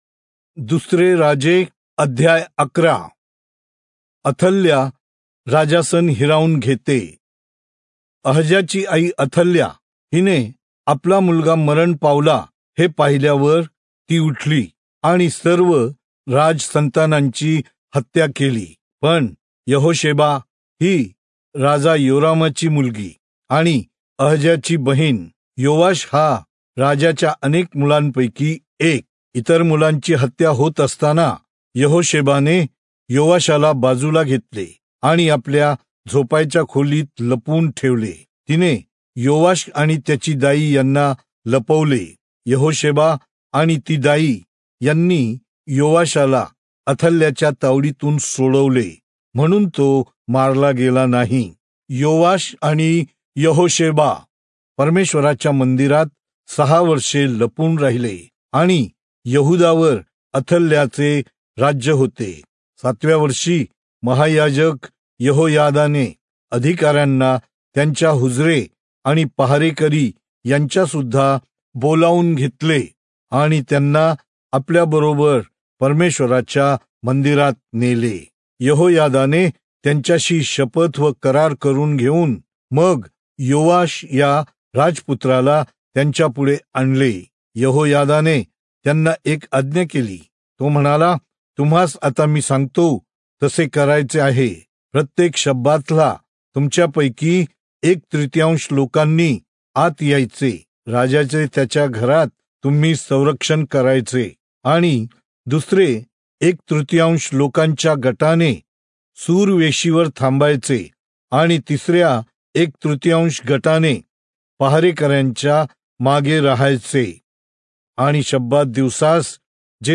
Marathi Audio Bible - 2-Kings 15 in Irvmr bible version